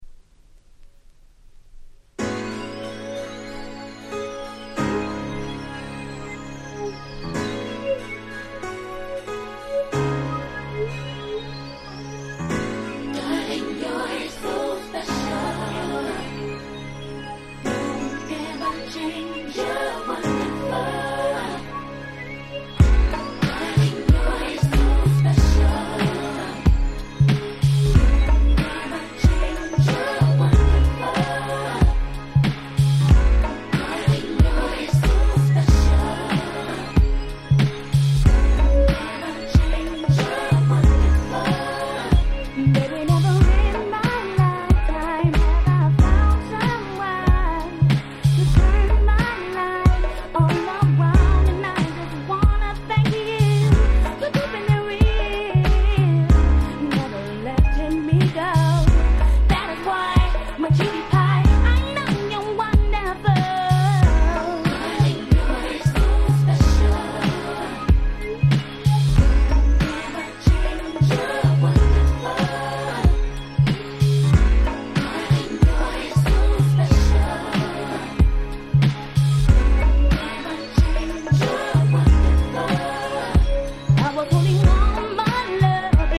96' Nice R&B Album !!